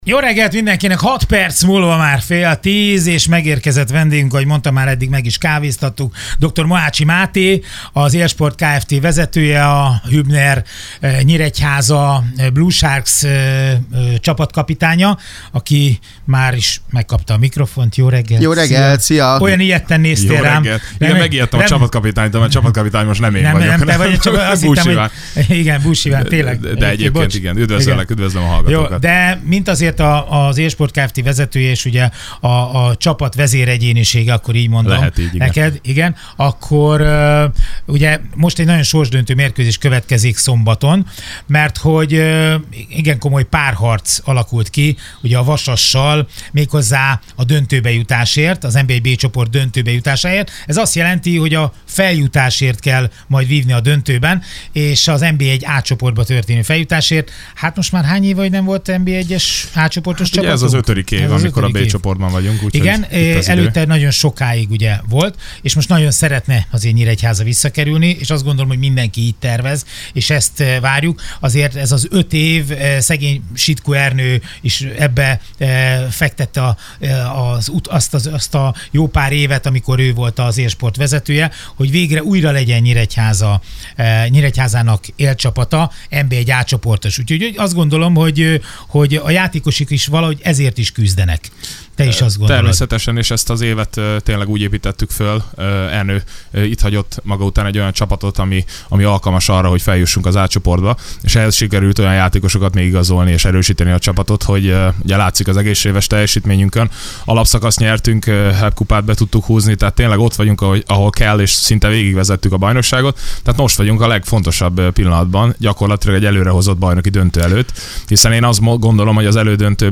Vendégségben a Sunshine Rádióban